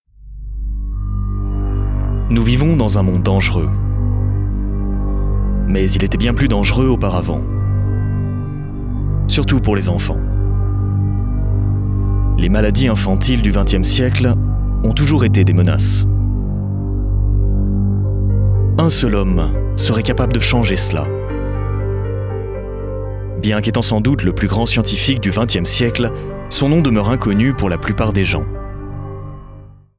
Documentaries
My natural voice lies in the middle-low range and is perfect to inspire and create trust with a touch of warmth and accessibility – ideal for corporate jobs, e-learnings and other “serious” projects that demand a reliable & inspiring yet warm and friendly delivery.